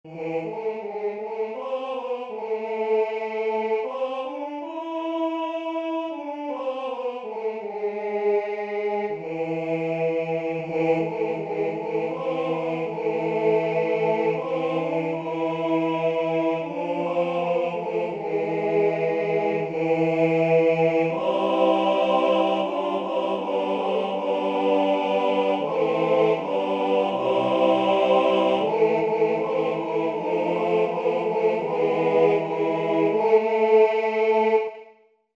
Комп'ютерне відтворення нот (mp3):